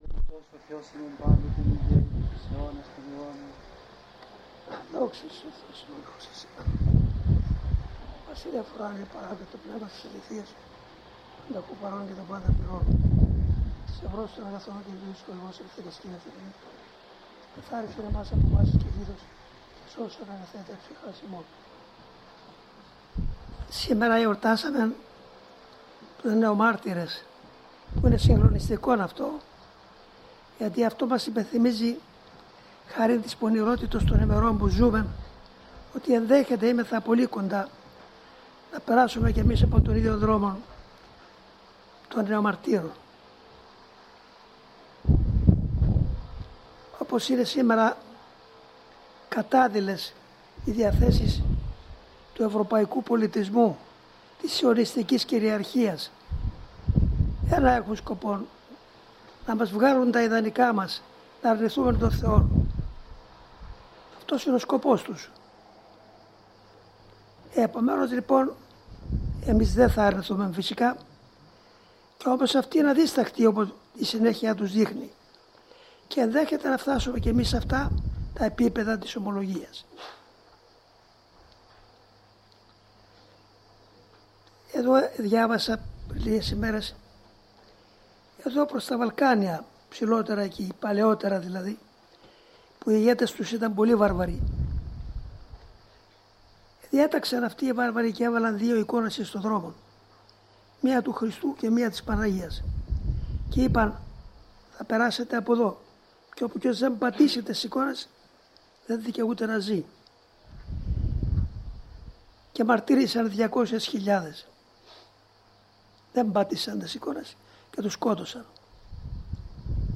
1/7/2002 Σύναξη της αδελφότητος